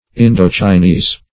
Indochinese \In`do*chi*nese"\, Indo-Chinese \In`do-Chi*nese"\,